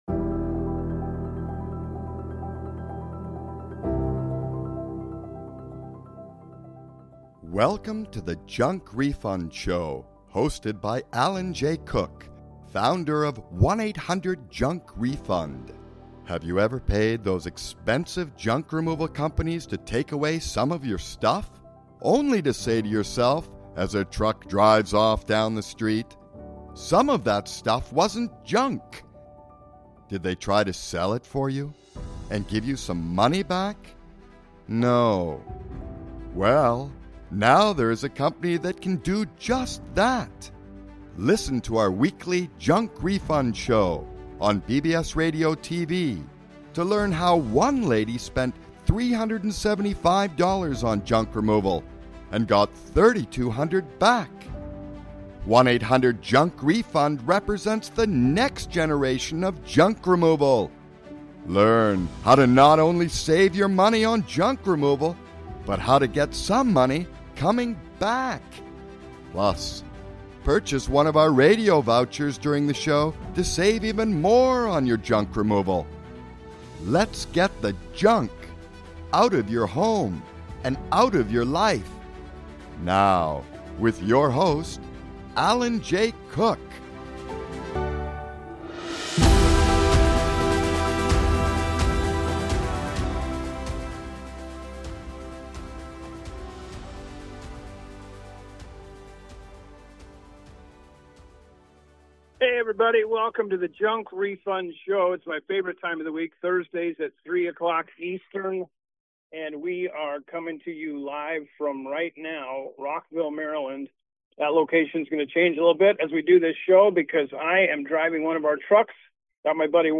Live from Rockville